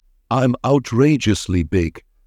Rounded, buoyant male voice, slightly deep with resilience, reflecting pride and optimism about his physique.